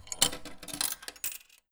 xrjamfeb2025/assets/freesound/384700_quarter_insert_edited.wav at f2eee081f26f2bf5061bdfc63cd6b4afdd2bf149